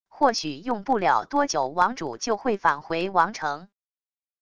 或许用不了多久王主就会返回王城wav音频生成系统WAV Audio Player